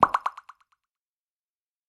Звуки уведомления чата
Вот альтернативный вариант звука подписки в браузере